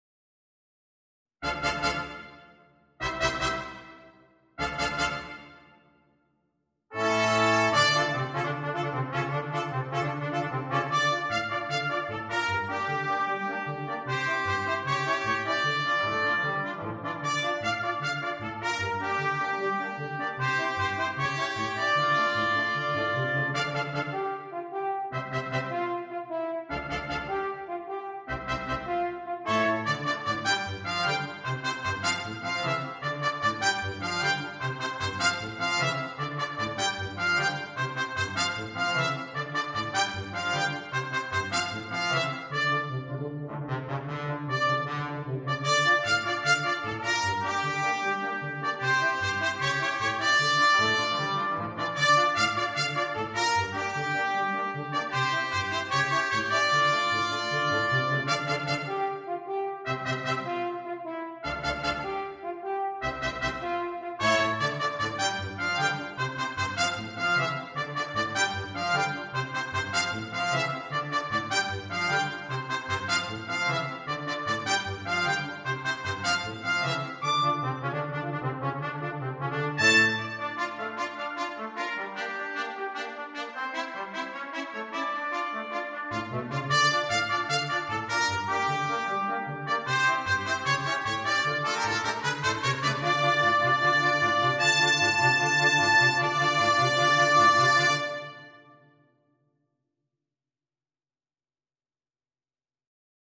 Ноты для брасс-квинтета